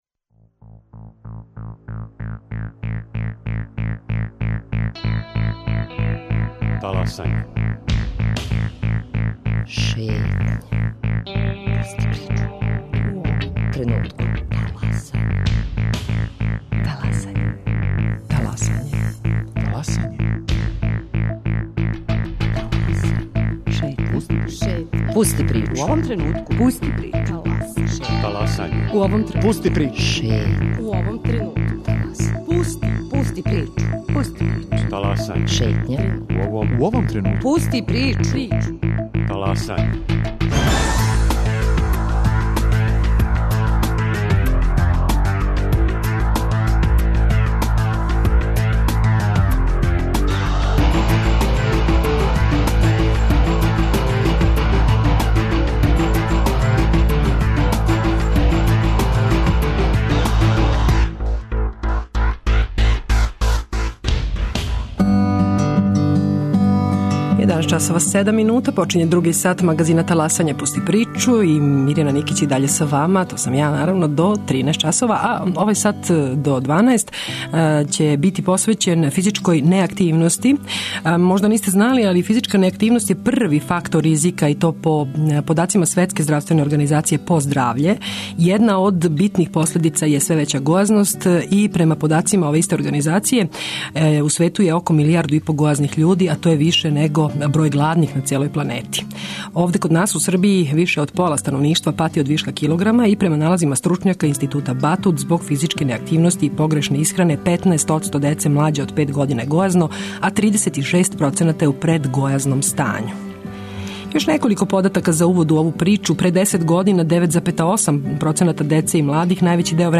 Радио Београд 1, 11.05